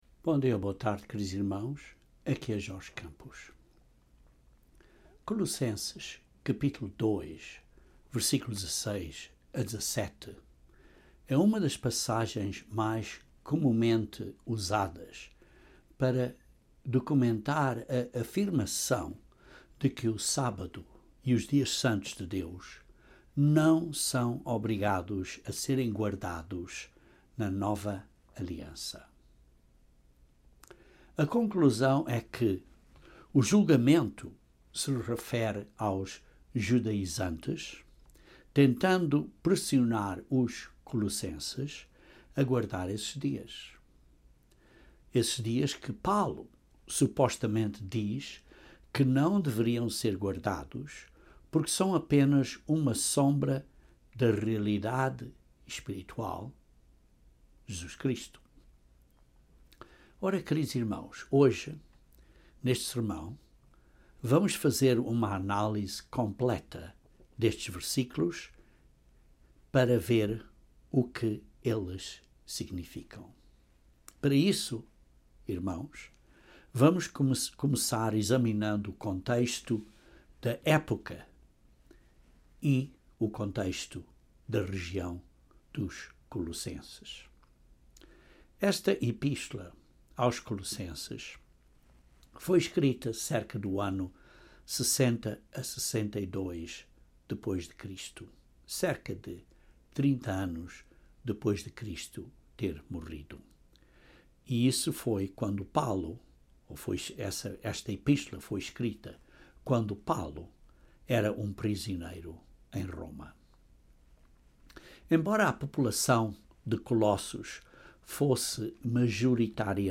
Neste sermão vamos fazer uma análise completa desses versículos para ver o que eles significam.